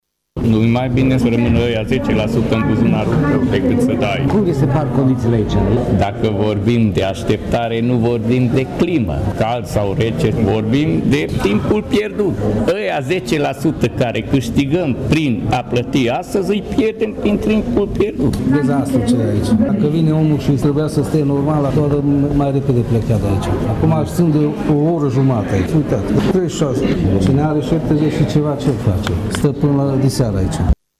Majoriatatea dintre ei s-au declarat nemulțumiți că au avut de stat mult pe holul Primăriei Tîrgu-Mureș, unde a fost căldură greu de sportat: